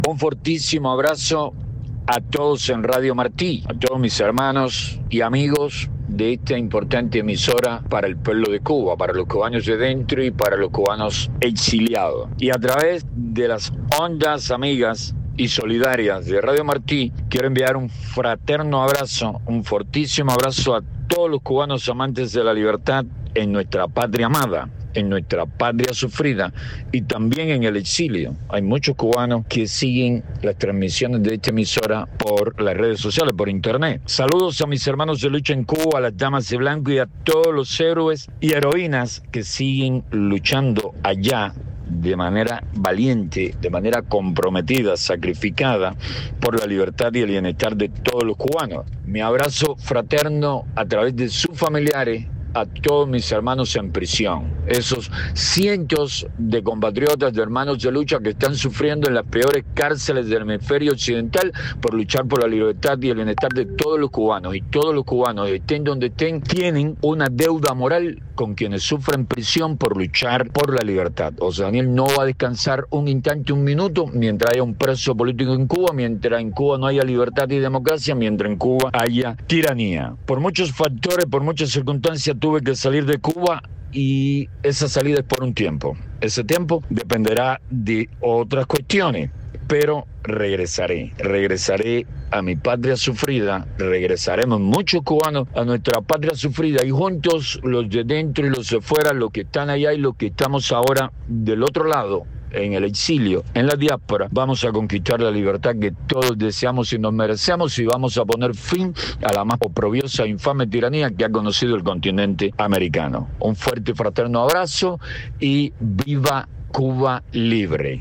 José Daniel Ferrer en conversación exclusiva con Martí Noticias